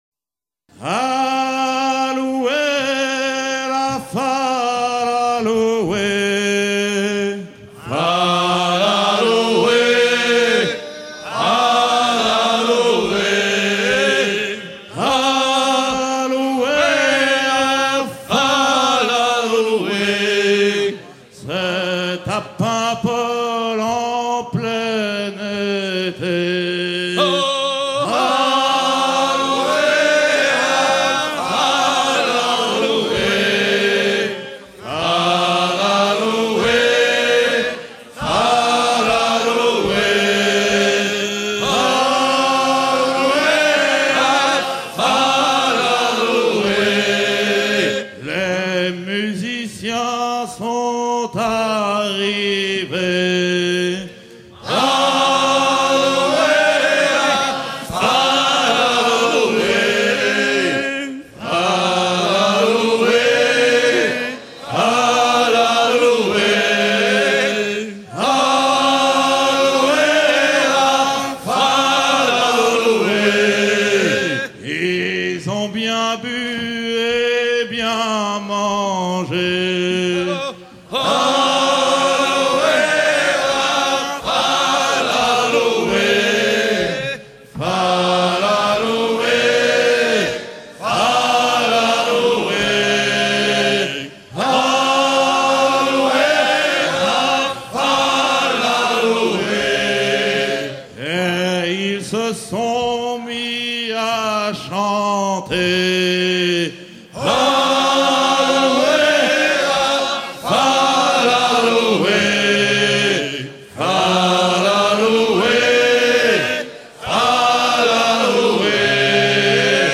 gestuel : à déhaler
circonstance : maritimes
Pièce musicale éditée